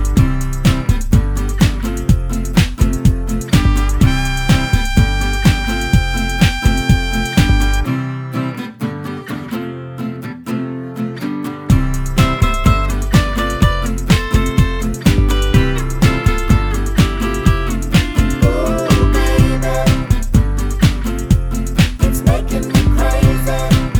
Duet Version Pop (1990s) 3:46 Buy £1.50